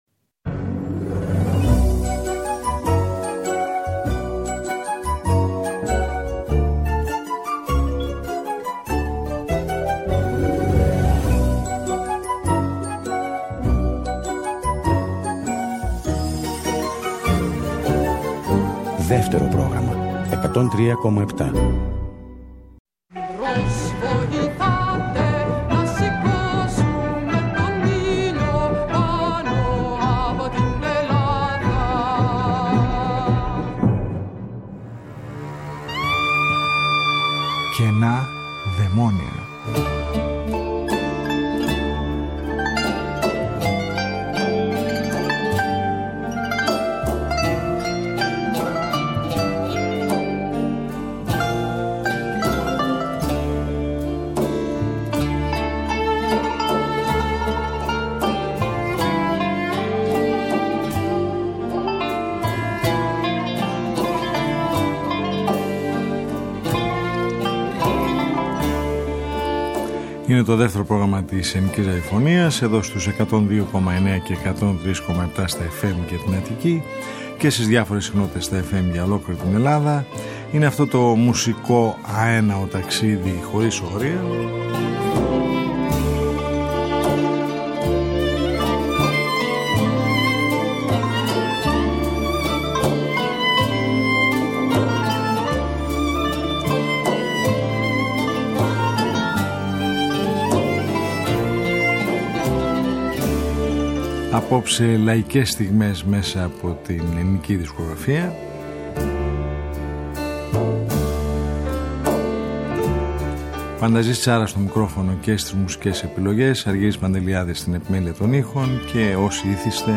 Μια ραδιοφωνική συνάντηση κάθε Σαββατοκύριακο που μας οδηγεί μέσα από τους ήχους της ελληνικής δισκογραφίας του χθες και του σήμερα σε ένα αέναο μουσικό ταξίδι.